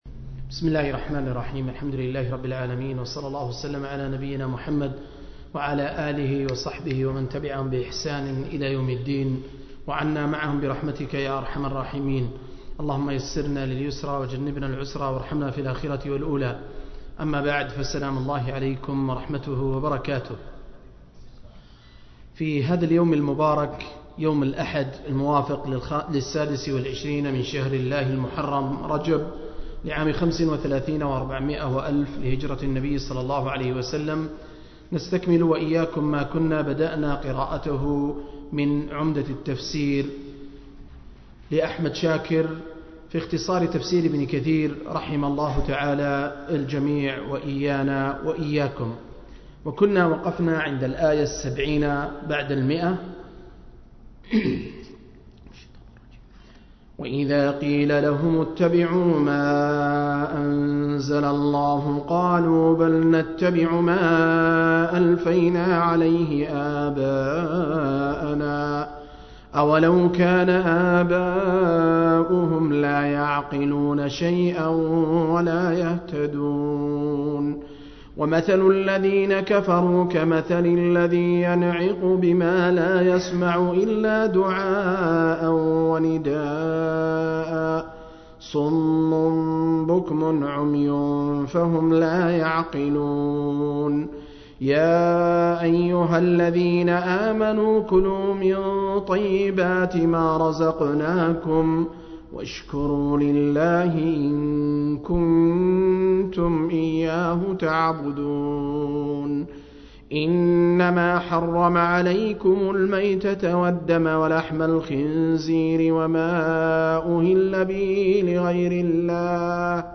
034- عمدة التفسير عن الحافظ ابن كثير – قراءة وتعليق – تفسير سورة البقرة (الآيات 176-170)
المجلس الرابع والثلاثون: تفسير سورة البقرة (الآيات 176-170)